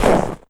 High Quality Footsteps
STEPS Snow, Run 08.wav